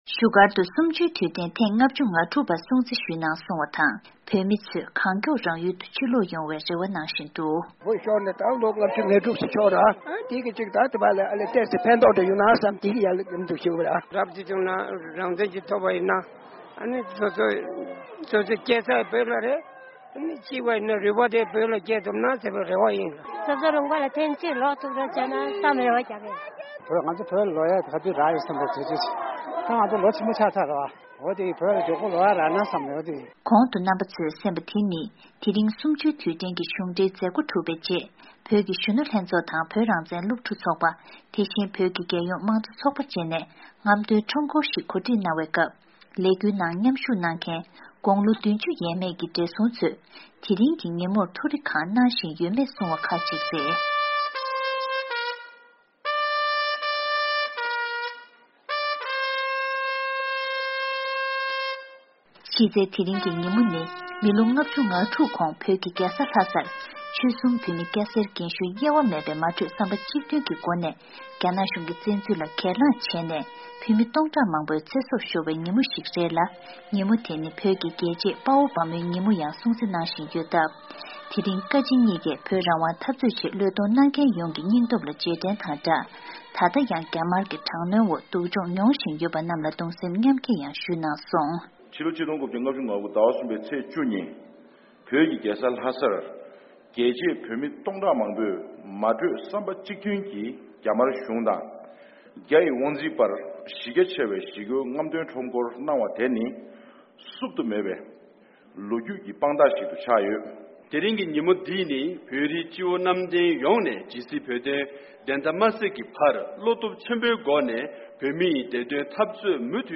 དེ་རིང་༢༠༡༥་ལོའི་ཕྱི་ཟླ་གསུམ་པའི་ཚེས་༡༠་ཉིན་མོ་འདི་༡༩༥༩་ལོར་བོད་ཀྱི་རྒྱལ་ས་ལྷ་སར་བོད་མི་ཡོངས་ཀྱིས་རྒྱ་ནག་གི་བཙན་འཛུལ་ལ་ངོ་རྒོལ་གྱེན་ལངས་བྱས་ནས་ལོ་༥༦་འཁོར་པའི་དུས་དྲན་ཡིན་ཞིང་། གསུམ་བཅུའི་དུས་དྲན་གྱི་ཉིན་མོ་འདིར་འཛམ་གླིང་གི་ས་ཕྱོགས་གང་སར་ཡོད་པའི་བོད་པ་ཚོས་ཡིད་གདུང་དྲག་པོའི་སྒོ་ནས་དུས་དྲན་འདི་སྲུང་བརྩི་ཞུས་ཡོད་པ་མ་ཟད། གཤམ་ལ་བཞུགས་སྒར་རྡ་རམ་ས་ལའི་གསུམ་བཅུའི་དུས་དྲན་གྱི་མཛད་ སྒོ་དང་དེ་བཞིན་བོད་པ་རྒན་གྲས་ཁག་ཅིག་ལ་བཅའ་འདྲི་ཞུས་ཡོད།།